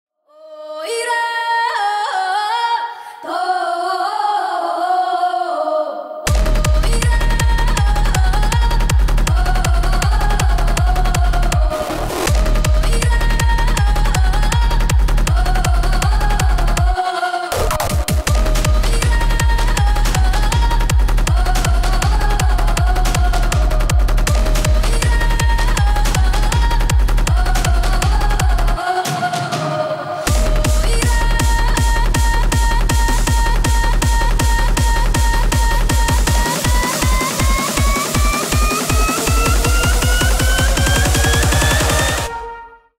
Электроника
без слов
клубные # ритмичные